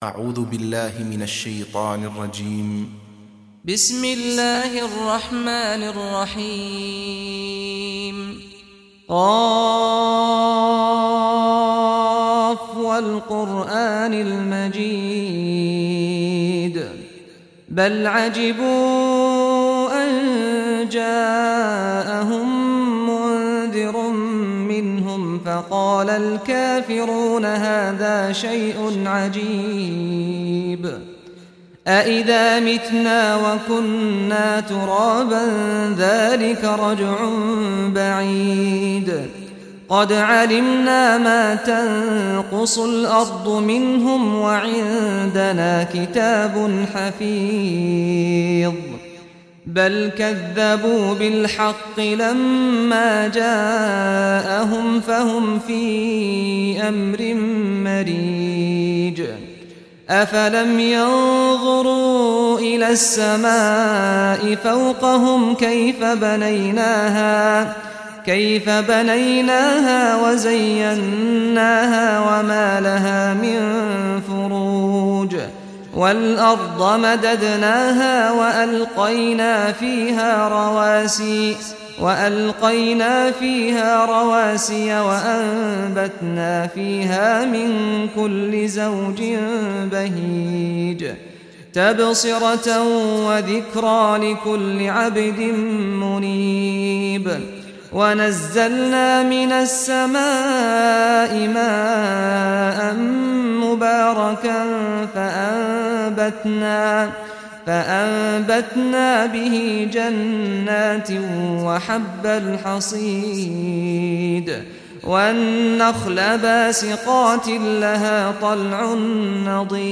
Surah Qaf Recitation by Sheikh Saad al Ghamdi
Surah Qaf, listen or play online mp3 tilawat / recitation in Arabic in the beautiful voice of Sheikh Saad al Ghamdi.